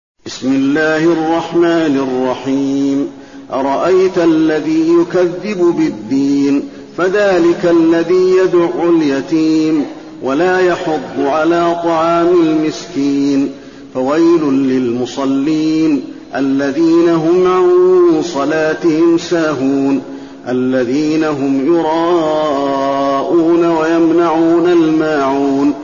المكان: المسجد النبوي الماعون